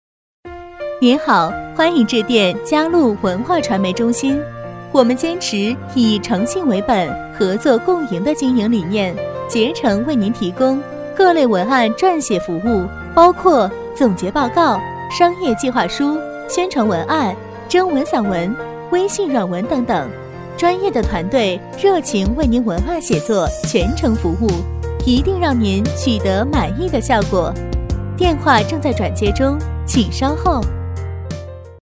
女C3-电话彩铃《嘉路文化传媒中心》
女C3-百变女王 温柔知性
女C3-电话彩铃《嘉路文化传媒中心》.mp3